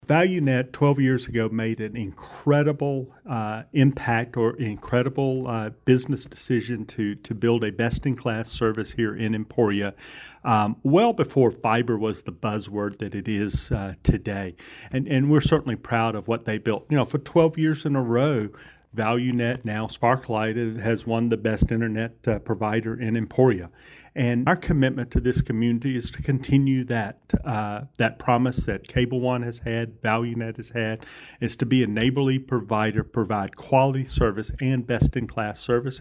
during an interview with KVOE News